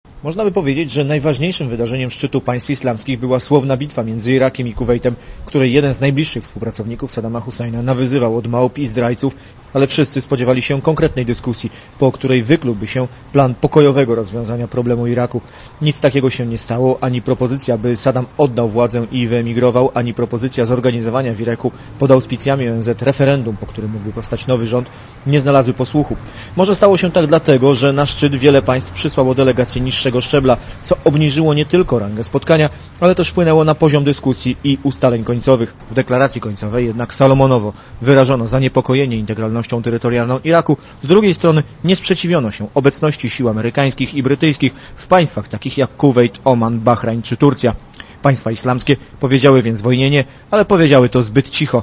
Znad Zatoki preskiej Reporter Radia Zet (404Kb)